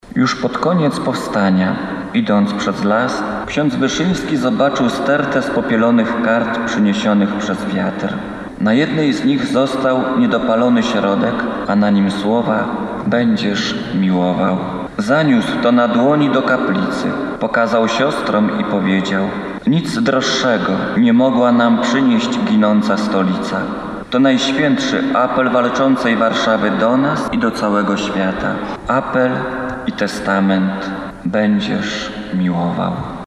Za nami kolejna comiesięczna msza święta w intencji w intencji beatyfikacji kardynała Stefana Wyszyńskiego. Wierni zgromadzili się w archikatedrze warszawskiej na Starym Mieście.